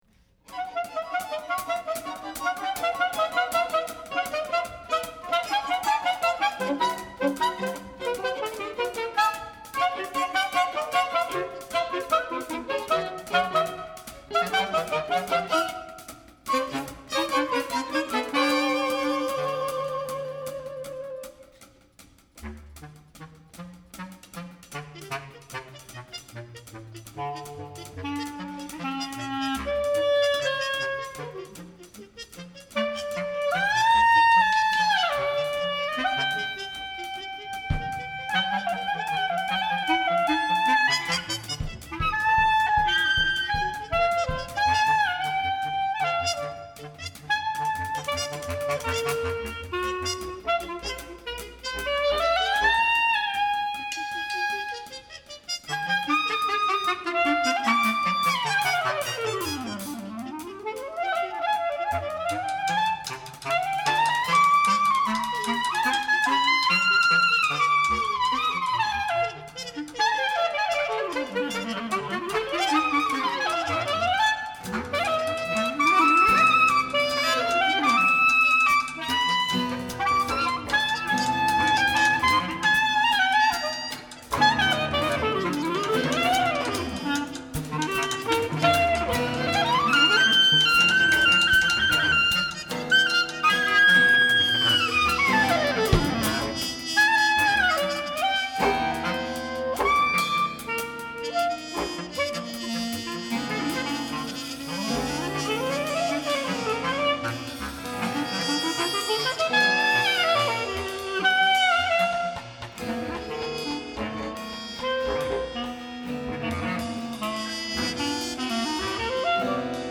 trumpet
bass clarinet/clarinet
tenor sax/clarinet
baritone and alto saxes/flute
violin
contrabass/electric bass/electronics
guitar/electronics